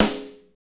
SNR MTWN 04L.wav